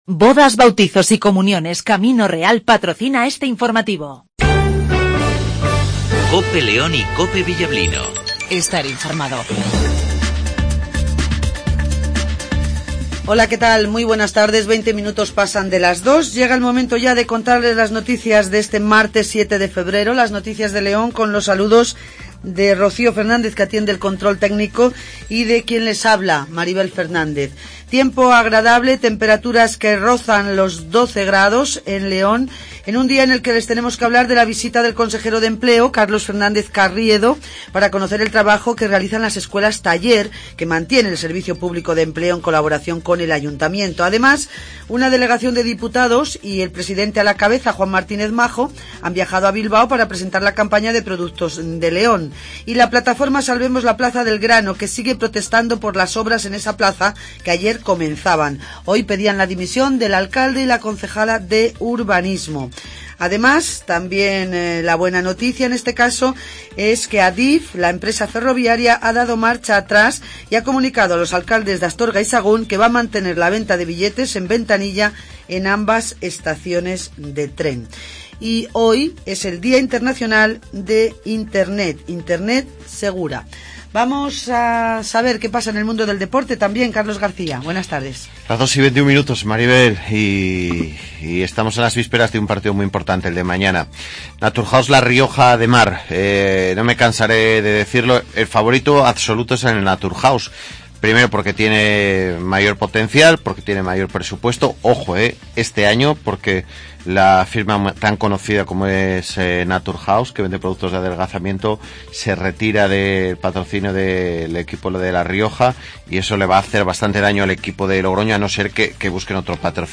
Gritos Manifestación " Plaza del Grano "